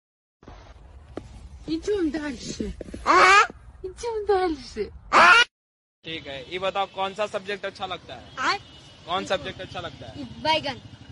suara kucing hwaaa yang asli